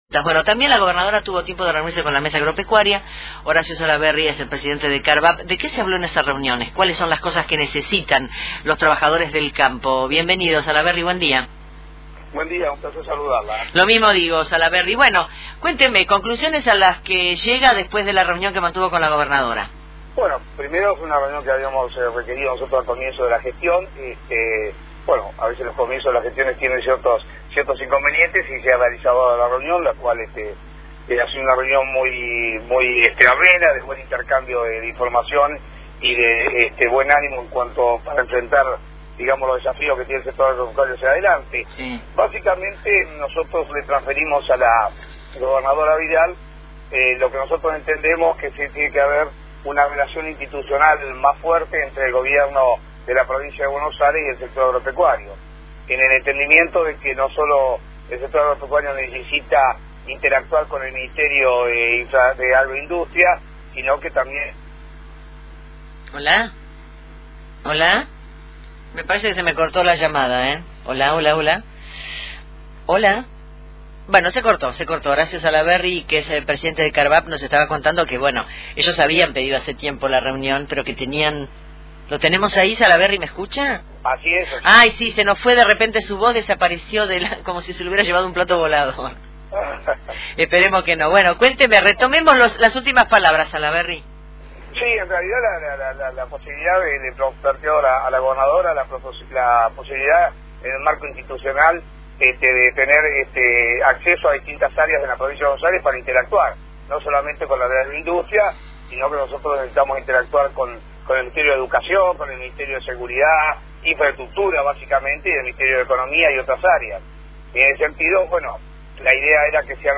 En diálogo con Radio Provincia